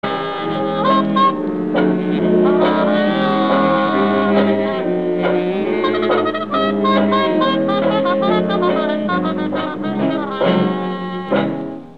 джаз и блюз: